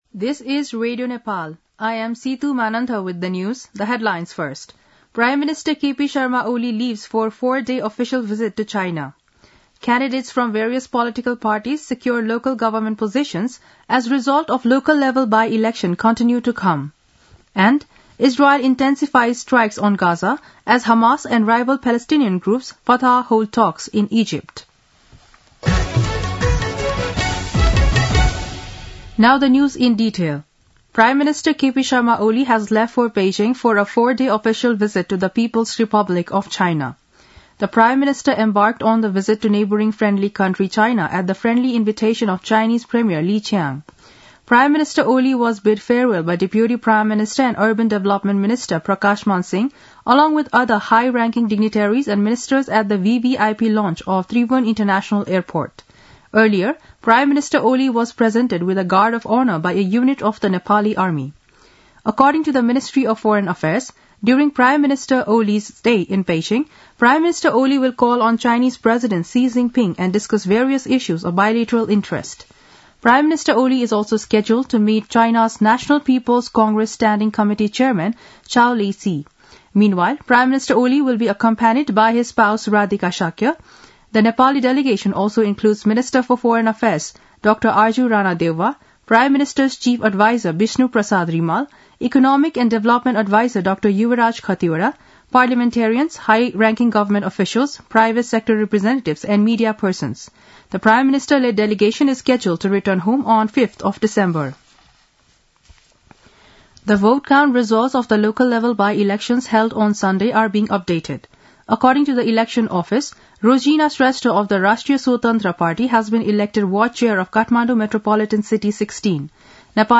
दिउँसो २ बजेको अङ्ग्रेजी समाचार : १८ मंसिर , २०८१
2-pm-english-news-1-1.mp3